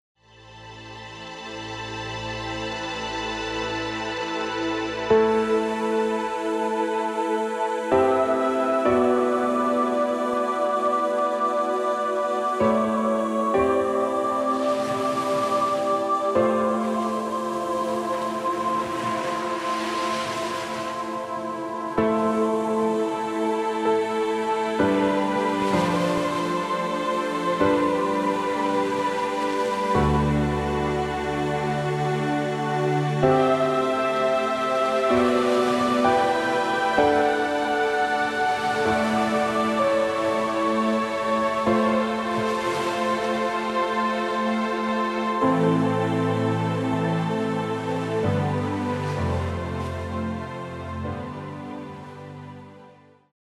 CLASSIC SONG COVERS
Outro voice over spoken by